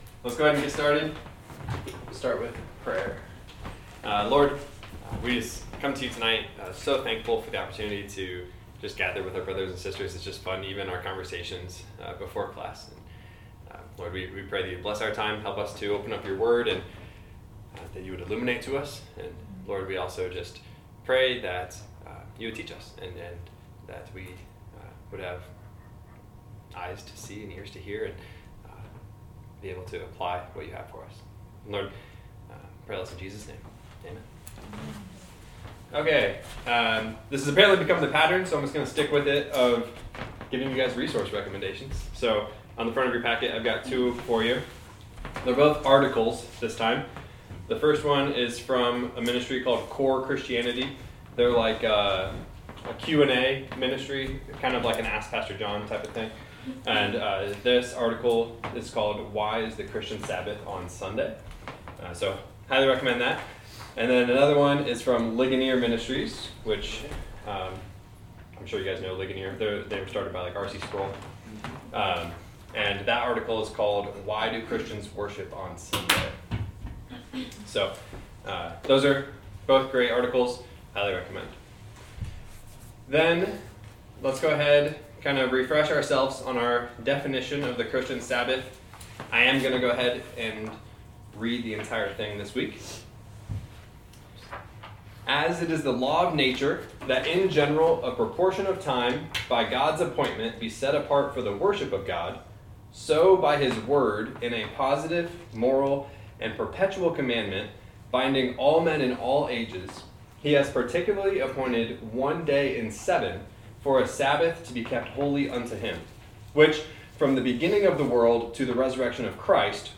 Meaning the audio is recorded from a mic that picks up the whole room and has only received a minimum amount of editing afterwards.